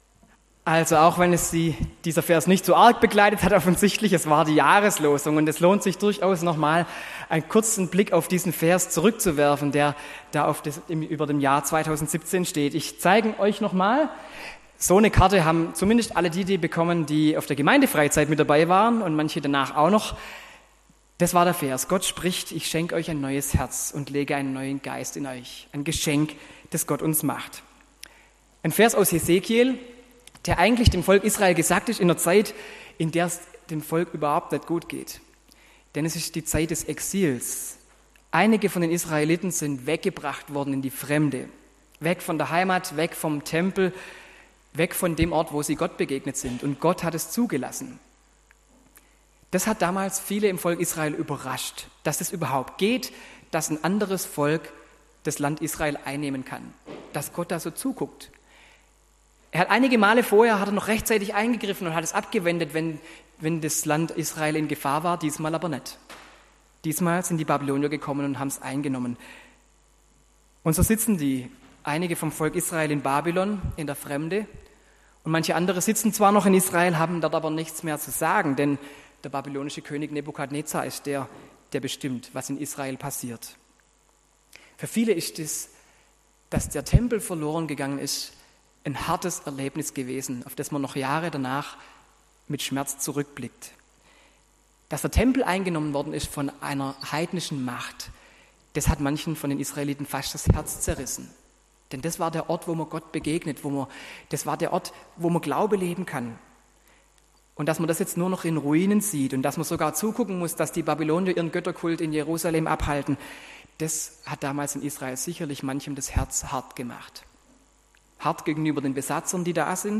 Predigt am Altjahrsabend : Das neue Herz (Jahreslosung 2017)
predigt-am-altjahrsabend-das-neue-herz-jahreslosung-2017